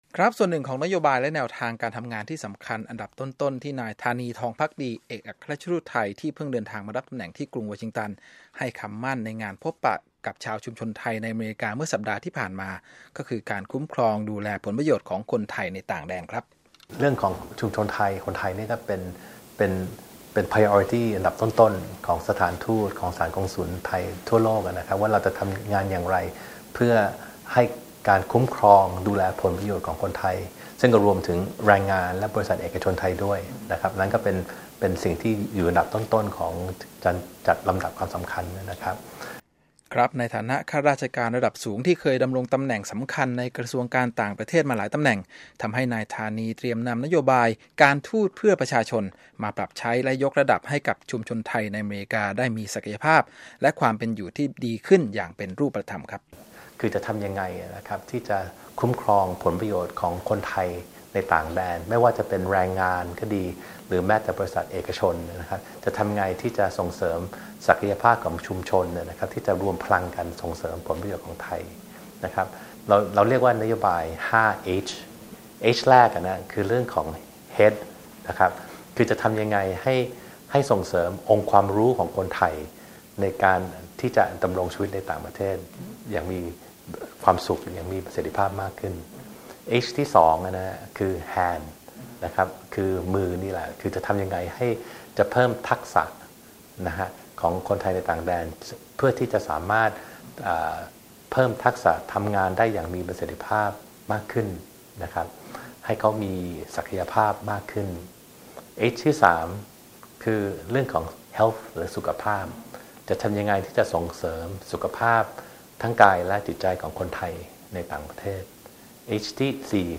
Interview Thai Ambassador pt. 2